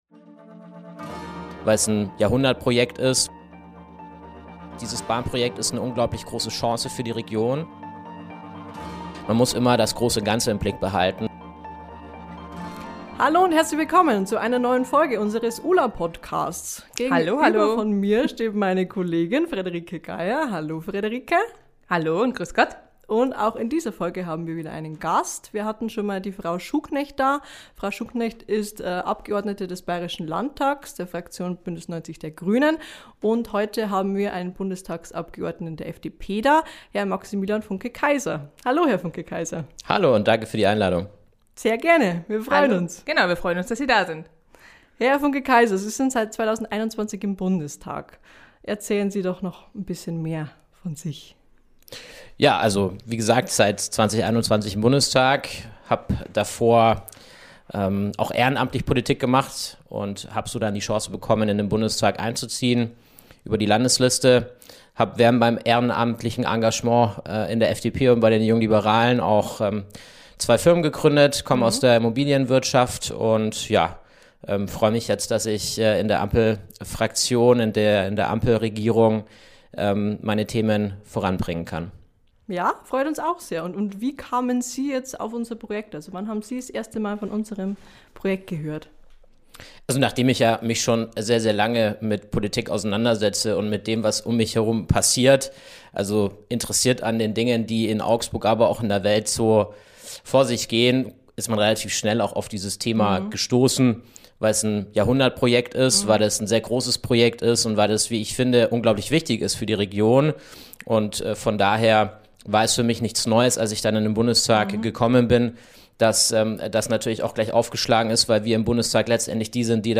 Nutzen für die Region? Na klar, aber wir dürfen auch das große Ganze nicht aus dem Blick verlieren. Ein Gespräch mit Maximilian Funke-Kaiser über Digitalisierung bei der Bahn, das neue Deutschlandtempo und wie er zwischen Augsburg und Berlin pendelt.